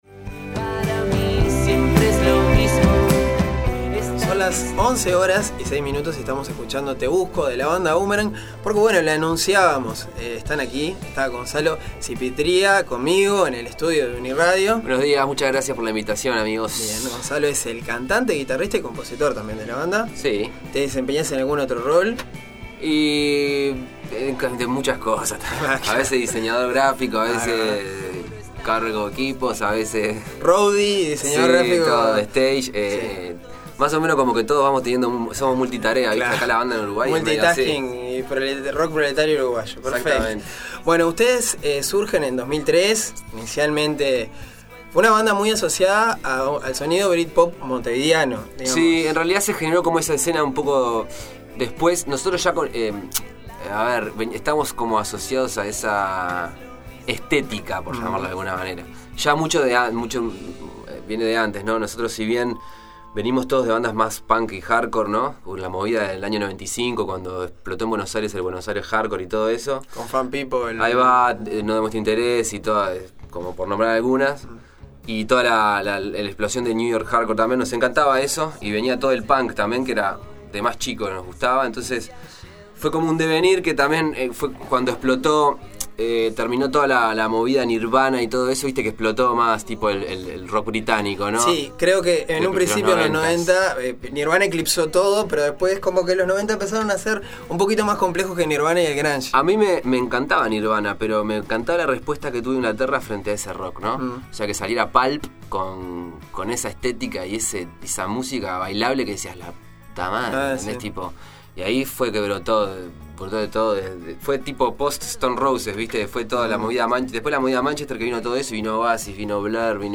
Conversamos sobre su nuevo material y escuchamos algo de su música en vivo.